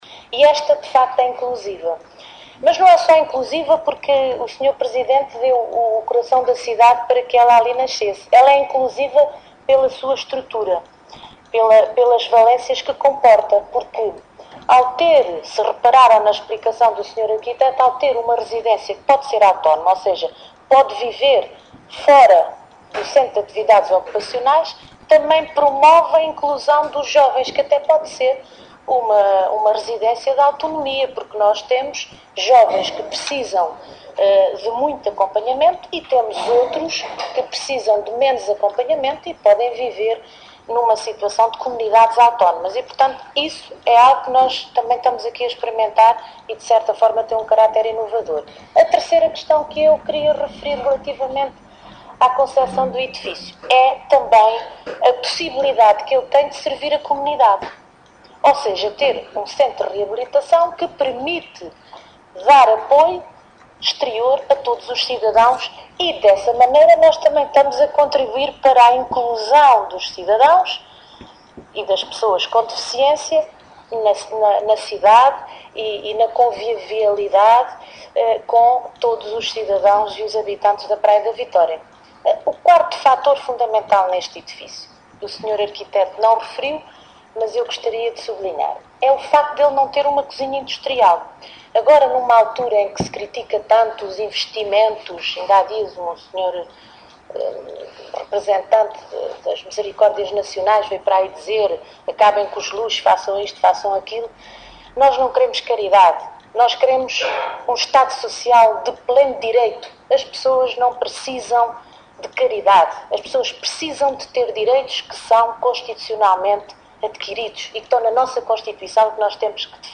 Ana Paula Marques falava na cerimónia de apresentação do projeto do Centro de Atividades Ocupacionais da Associação de Pais e Amigos das Crianças com Deficiência do Concelho da Praia da Vitória, uma obra que vai ter início brevemente, financiada pelo Governo Regional, no valor de cerca de 2,2 milhões de euros, a instalar num lote na cidade praiense cedido pela Câmara Municipal.